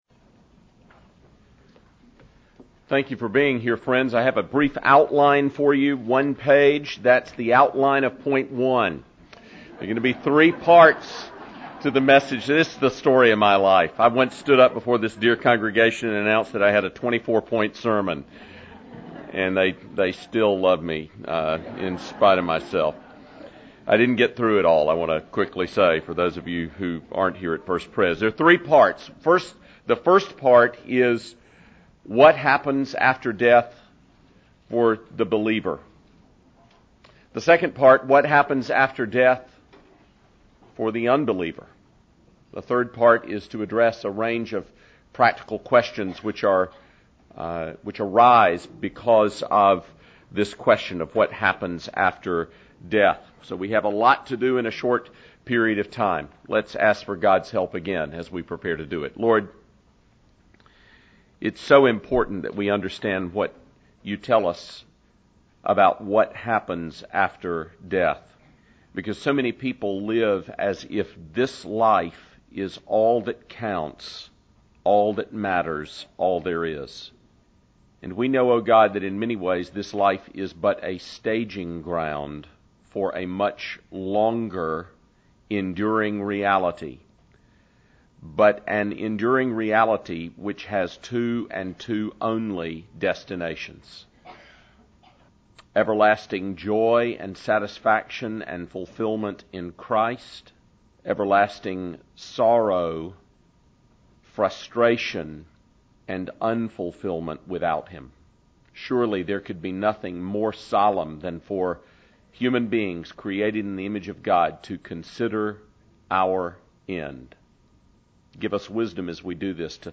“There Is Hope” Luncheon Series March 28, 2007 What Happens After Death?